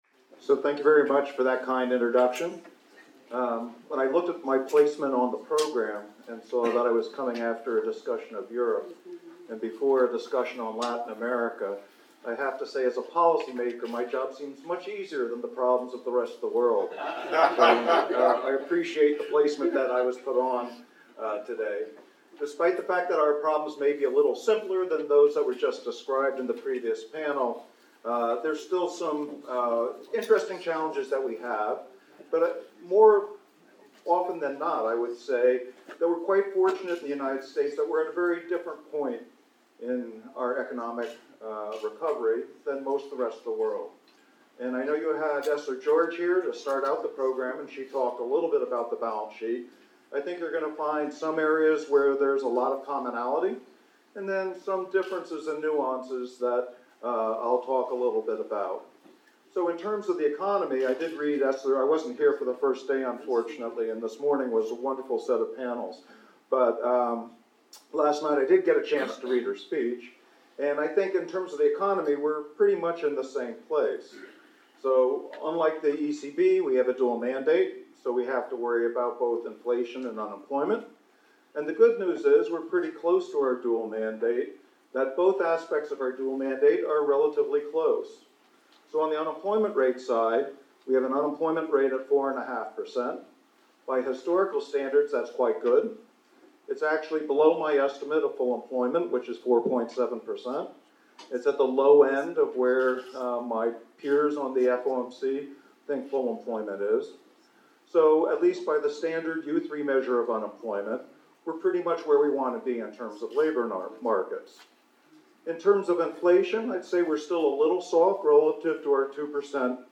The Federal Reserve Balance Sheet and Monetary Policy By Eric S. Rosengren April 19,2017 Annandale-On-Hudson, NY Annual Hyman P. Minsky Conference.
Speech Audio